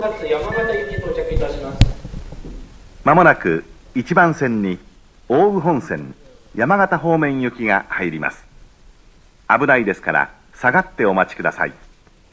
山形駅と比べると「奥羽本線、山形方面行き」と言った詳しい案内をしてくれる、親切な自動放送です。
アナウンスは上りが男声、下りが女性となっています。
上り接近放送(山形方面)
上りには駅員放送が被っていますが、こんな感じで駅員がアナウンスしてくれます。